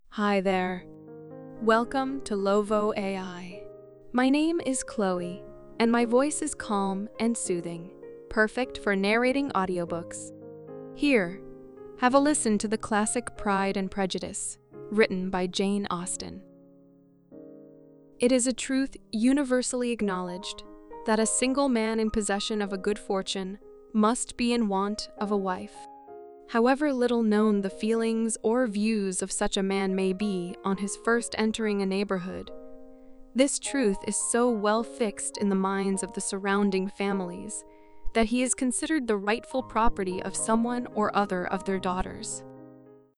LOVO AI example output